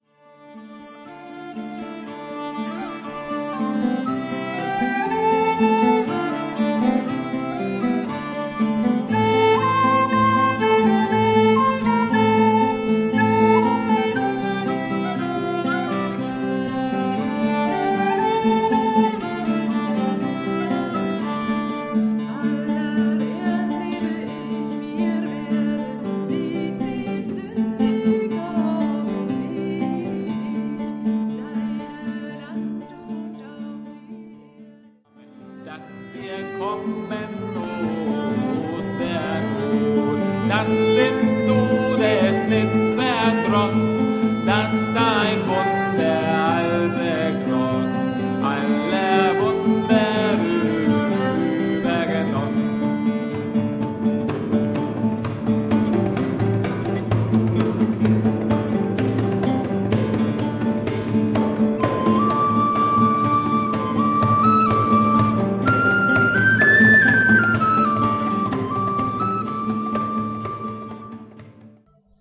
unsere cd ist ein mitschnitt unserer musiktreffen
in der mittelalterlichen st. servatius-kirche zu selent